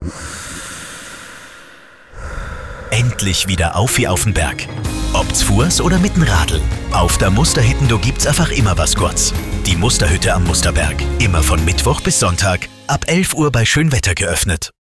Voice Clone
Radiowerbespot 02
Radiospot "Dialekt"
Immer fällt eine gewisse Monotonie auf, die Betonungen wirken oft übertrieben und künstlich.